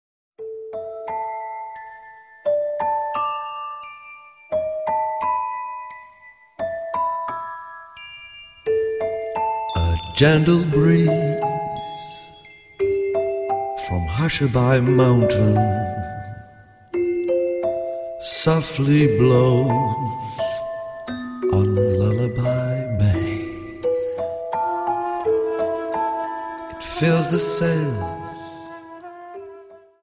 Dance: Slow Waltz